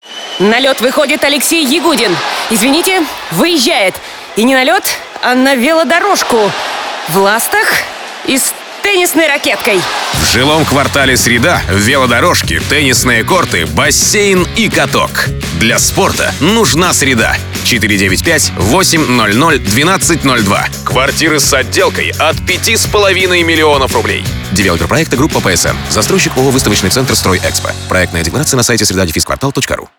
Радиореклама